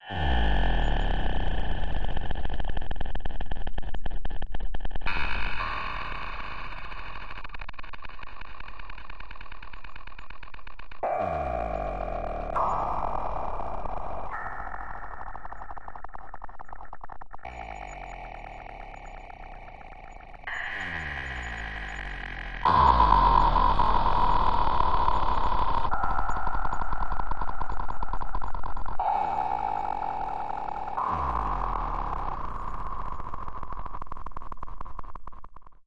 外星人打嗝
描述：一个非常胖的外星人，有消化问题。通过计算机合成生成样本。
标签： 怪异 外空 奇怪 外星人 超自然 UFO 外星人品种 空间 科幻 噪声 不寻常 合成
声道立体声